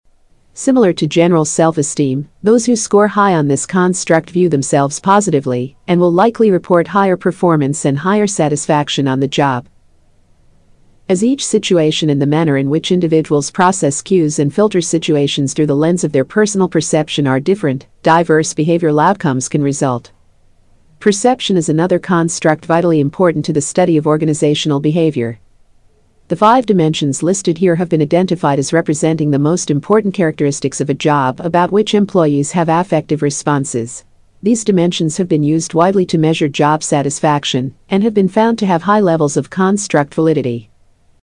女英109 美式英语 柔和的 略成熟 低沉|大气浑厚磁性|沉稳|娓娓道来|积极向上|神秘性感|亲切甜美|素人